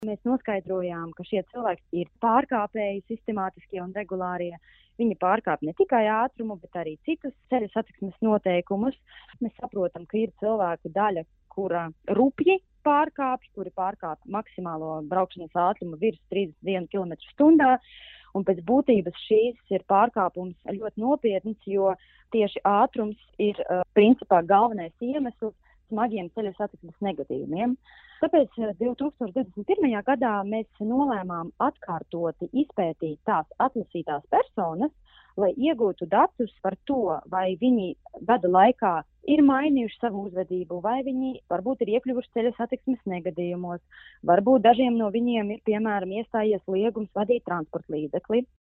RADIO SKONTO Ziņās Valsts policijas pētījums par atkārtotiem ātruma pārkāpumiem ceļu satiksmē